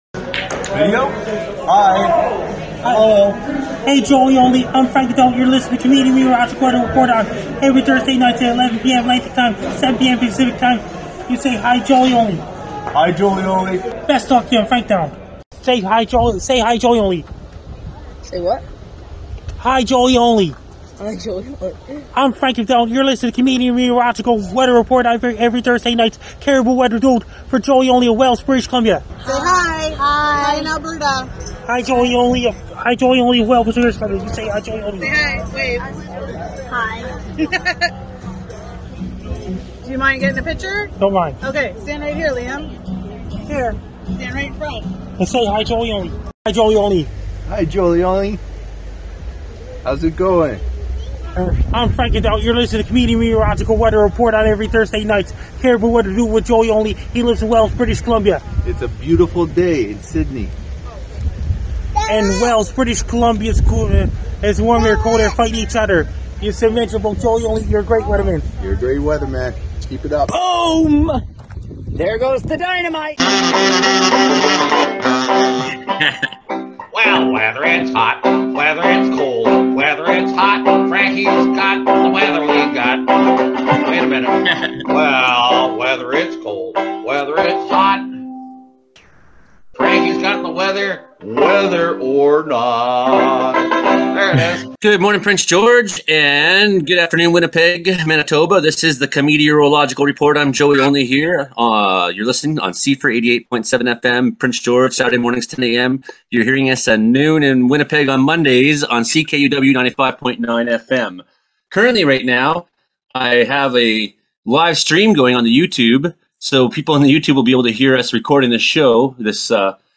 Comedeorology July 7: Thunder While We Record, Nasty Prairie Storms, Weather Jokes, Canada News